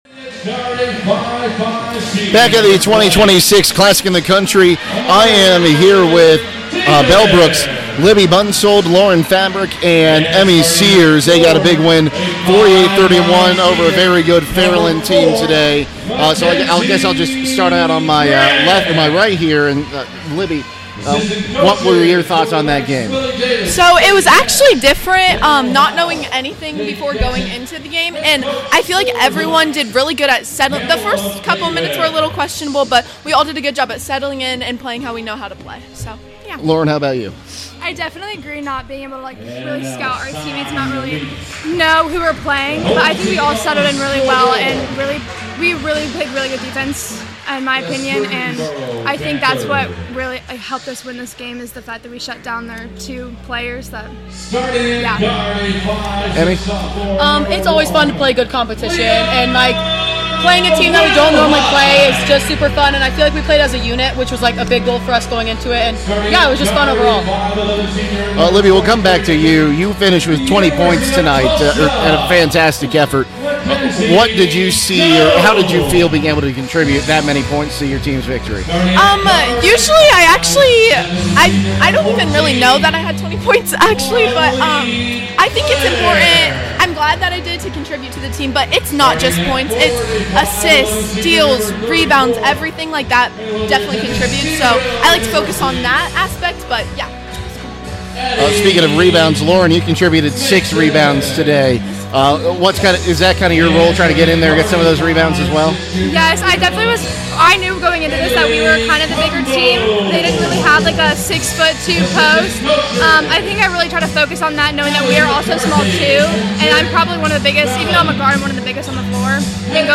CLASSIC 2026 – BELLBROOK PLAYER INTERVIEWS